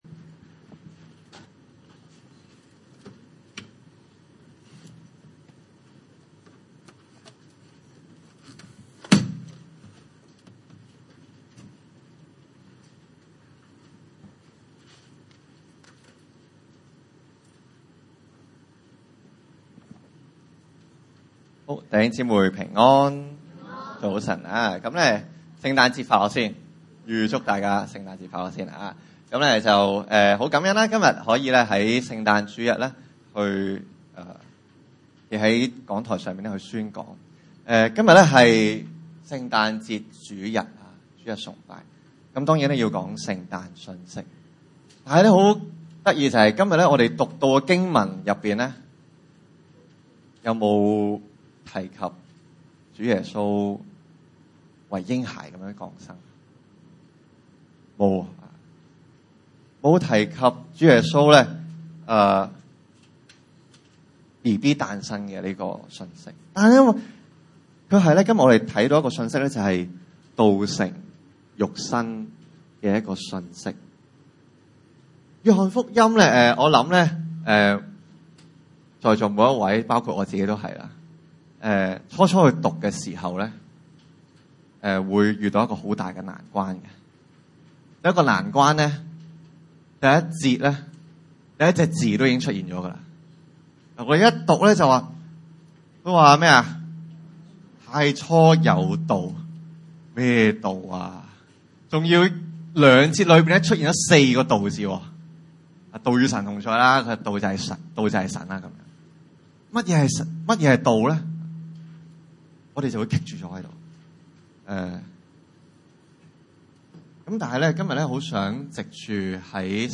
約翰福音1:1-18 崇拜類別: 主日午堂崇拜 1.